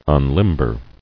[un·lim·ber]